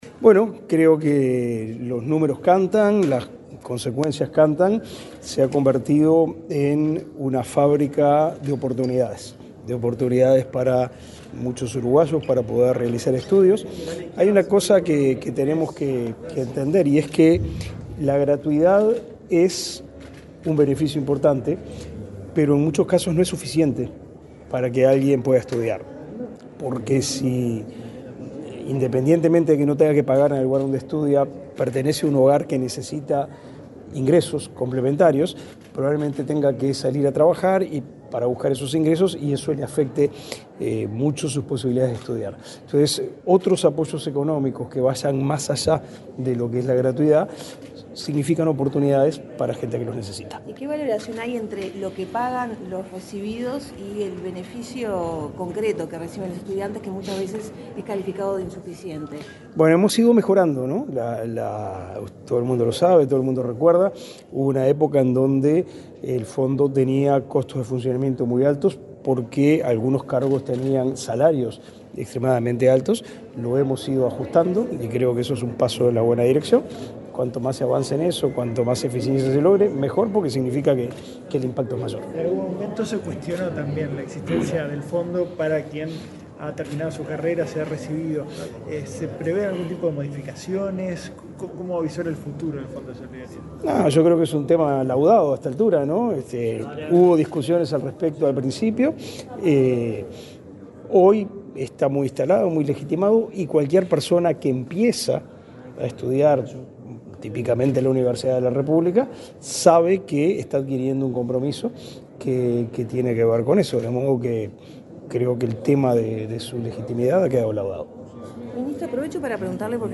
Declaraciones del ministro de Educación y Cultura, Pablo da Silveira
Declaraciones del ministro de Educación y Cultura, Pablo da Silveira 25/07/2024 Compartir Facebook X Copiar enlace WhatsApp LinkedIn El ministro de Educación y Cultura, Pablo da Silveira, dialogó con la prensa, antes de participar en el acto conmemorativo de los 30 años del Fondo de Solidaridad.